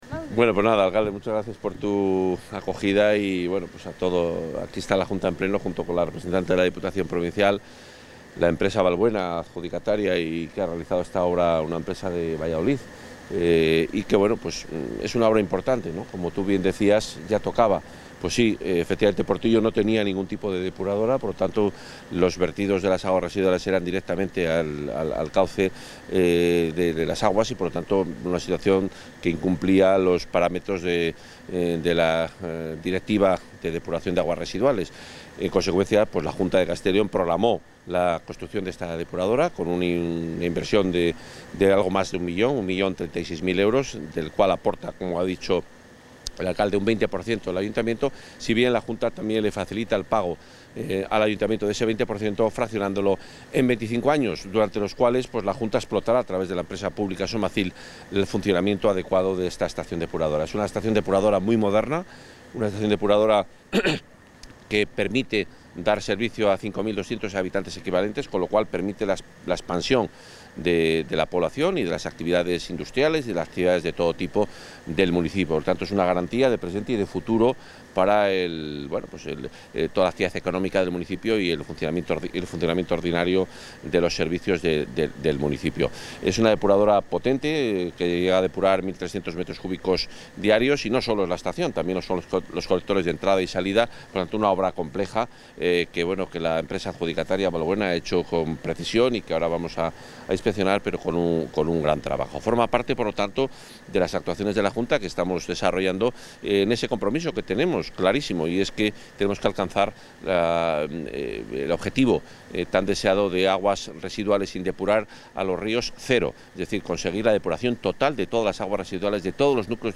Intervención consejero Fomento y Medio Ambiente en funciones.
El consejero de Fomento y Medio Ambiente en funciones, Juan Carlos Suárez-Quiñones, ha visitado esta mañana la estación depuradora de aguas residuales (EDAR) de la localidad vallisoletana de Portillo, que ha puesto fin a sus problemas de depuración.